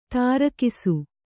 ♪ tārakisu